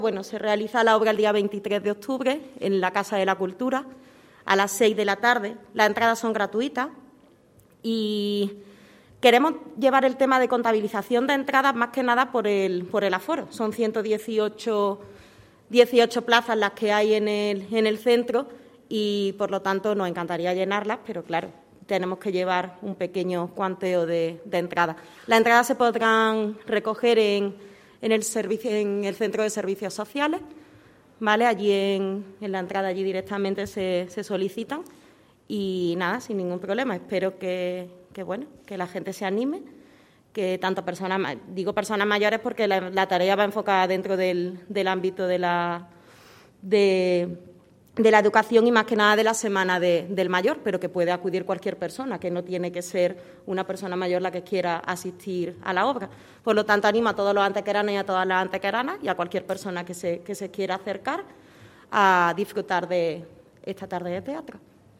La concejal delegada de Familia, Equidad, Accesibilidad, Educación y Sanidad, Sara Ríos, ha presentado el próximo desarrollo de una nueva iniciativa de carácter lúdico cultural que se ha querido organizar en torno a los actos conmemorativos de la Semana del Mayor en nuestra ciudad.
Cortes de voz